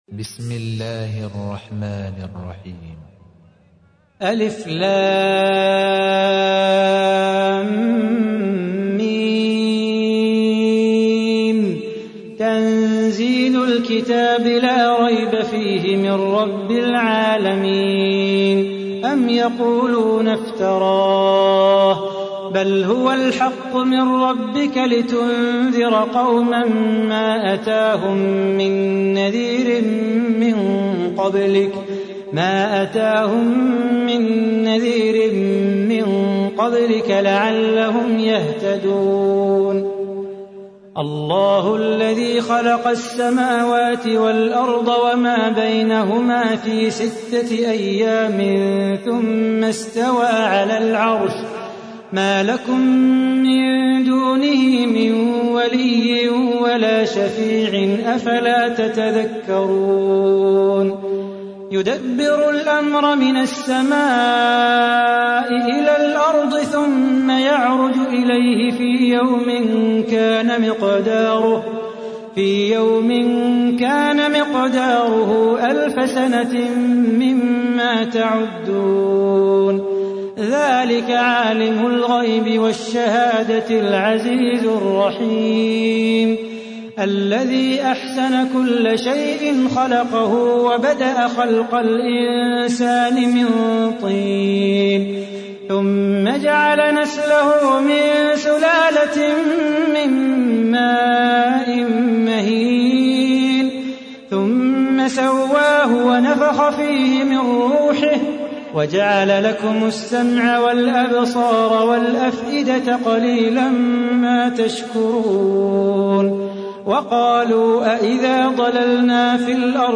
تحميل : 32. سورة السجدة / القارئ صلاح بو خاطر / القرآن الكريم / موقع يا حسين